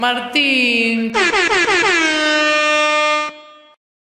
Air Horn